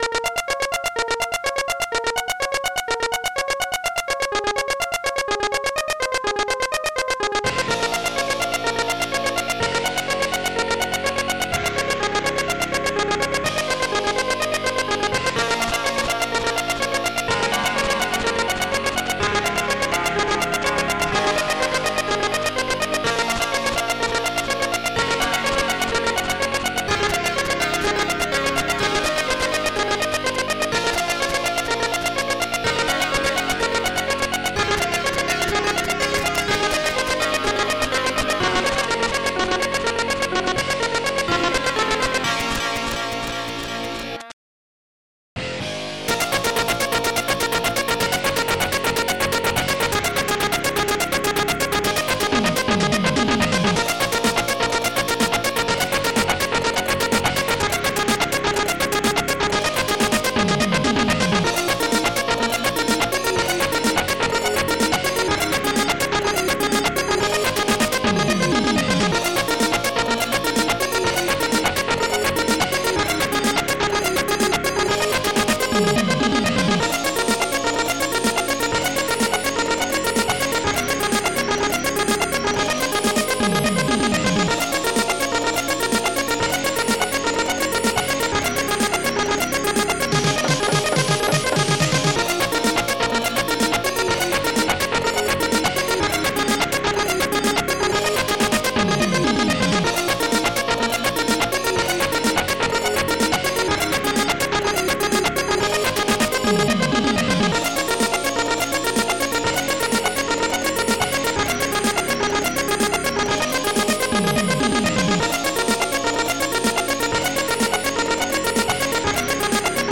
st-05:guitar2 st-01:noteman st-01:metalkeys st-05:minibass st-01:shaker st-05:snare4 st-01:popsnare2 st-01:bassdrum2